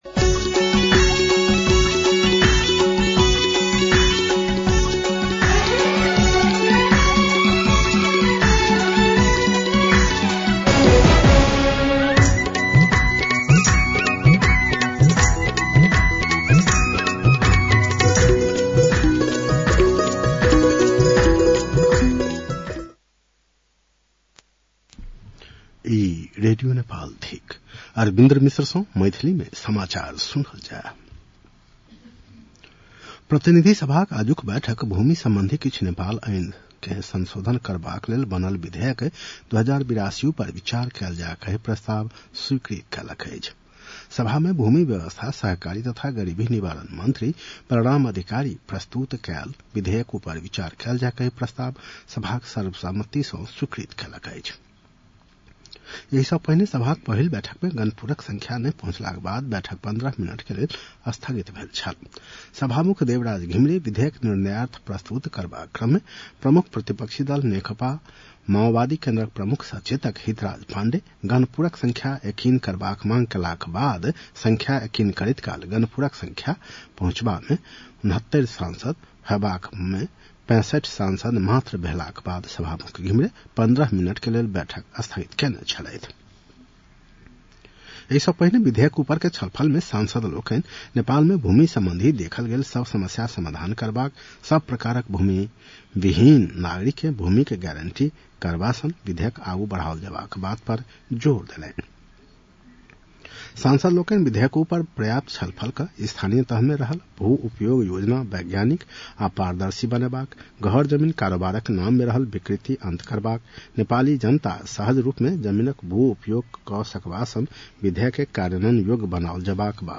An online outlet of Nepal's national radio broadcaster
मैथिली भाषामा समाचार : ६ जेठ , २०८२